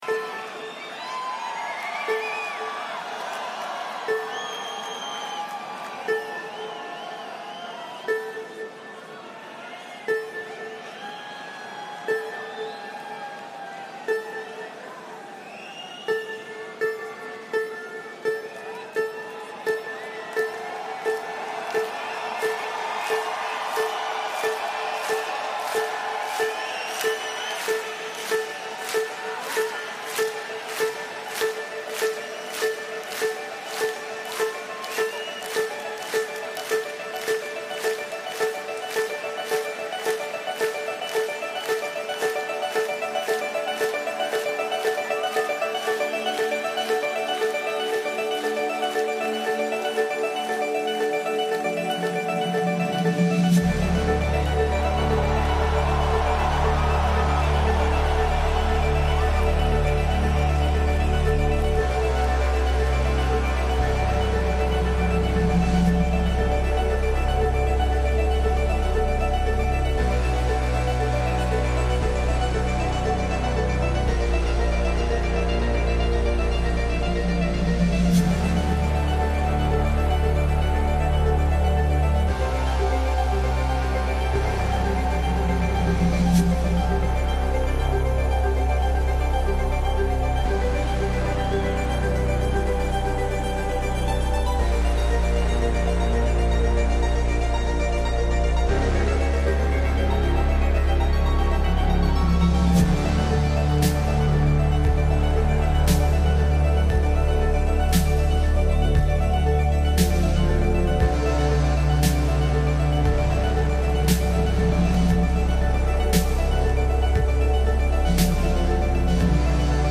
Live at Accor Arena 2024